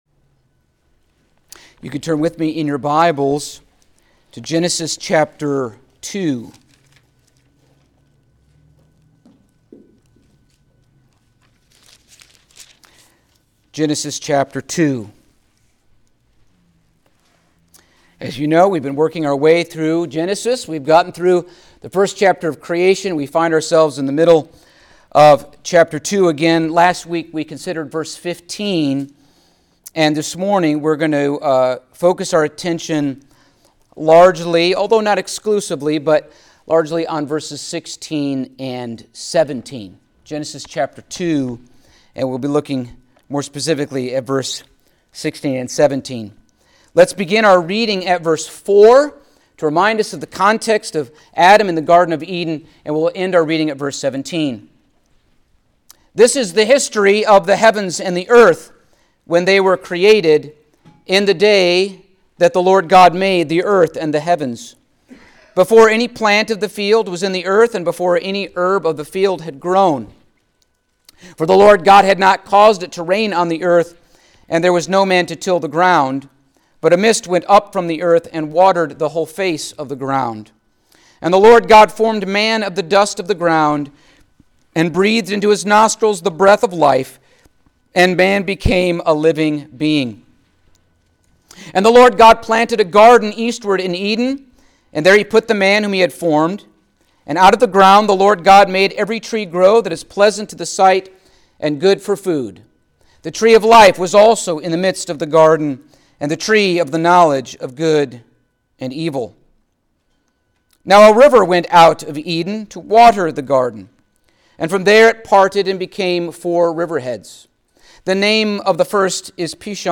Passage: Genesis 2:16-17 Service Type: Sunday Morning